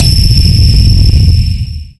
sonarTailWaterClose1.ogg